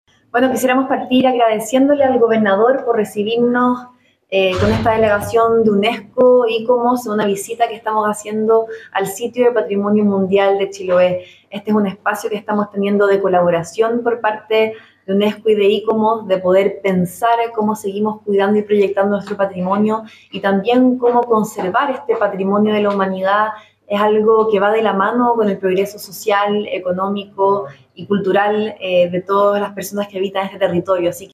En este sentido, la subsecretaria de Patrimonio, Carolina Pérez, destacó la importancia de la colaboración con las entidades internacionales para garantizar el cuidado de este legado cultural.